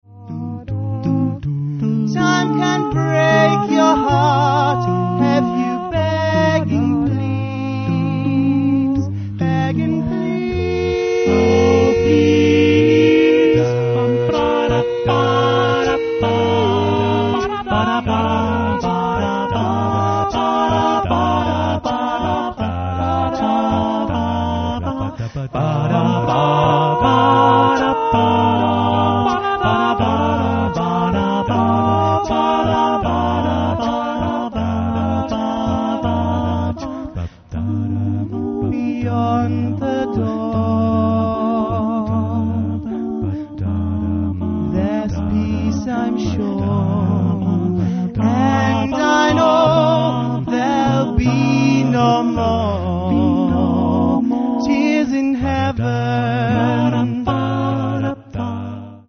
Hier ein paar kleine Ausschnitte aus unserem Konzert am 1. August 2002 als mp3-Dateien.